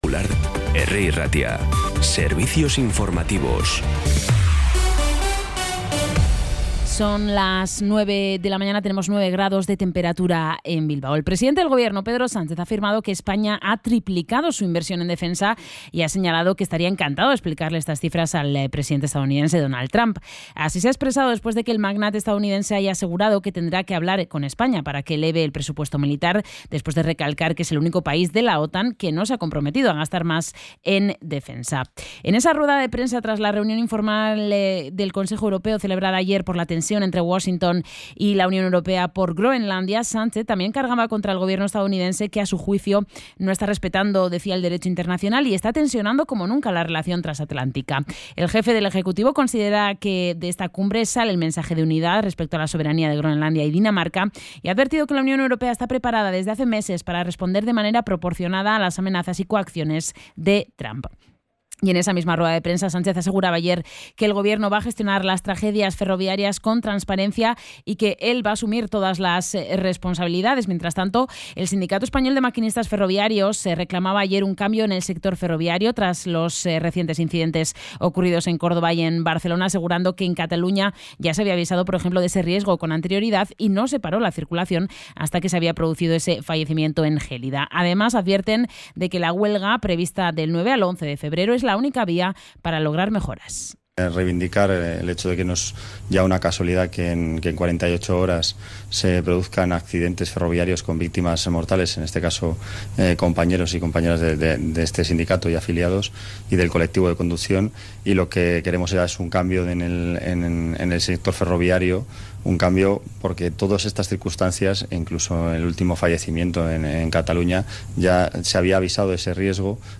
Las noticias de Bilbao y Bizkaia de las 9 , hoy 23 de enero
Los titulares actualizados con las voces del día. Bilbao, Bizkaia, comarcas, política, sociedad, cultura, sucesos, información de servicio público.